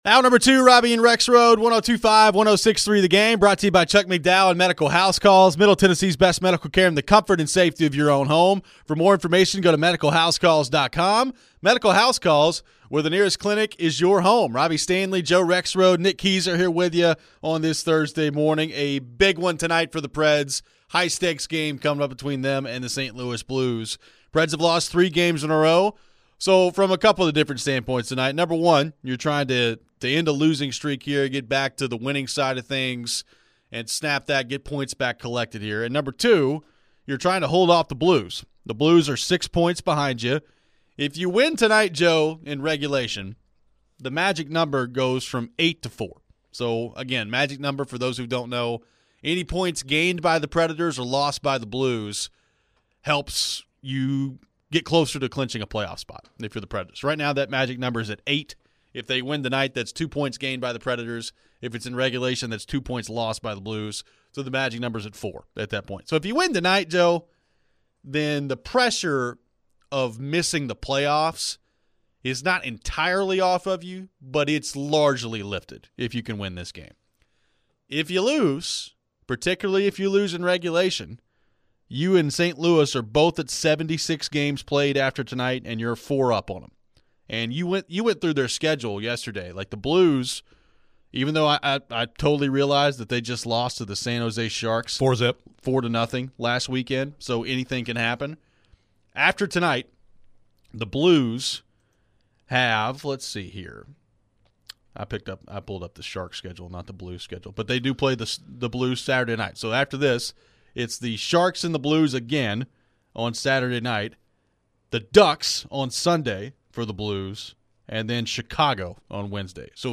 Nashville Predators head coach, Andrew Brunette, joined the show before their matchup against the Blues.